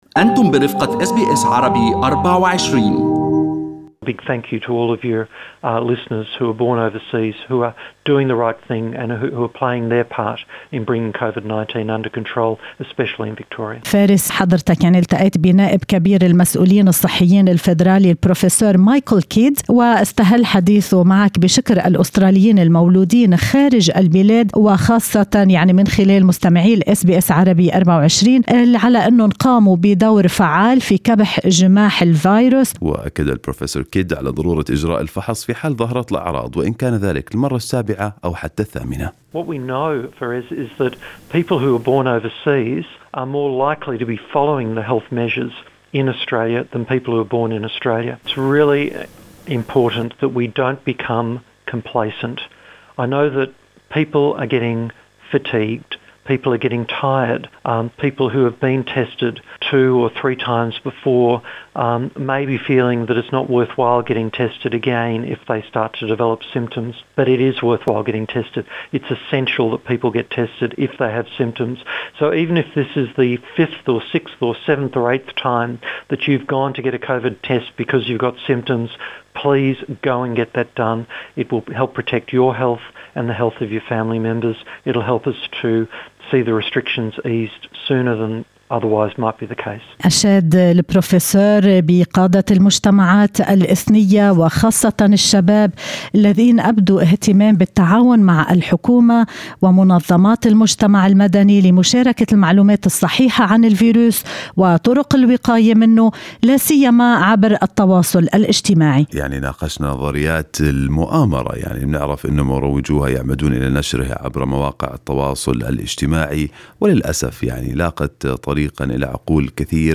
وقال كيد في حديث لأس بي أس عربي24 ان المولودين في الخارج أكثر التزاماً من غيرهم بالنصائح الطبية.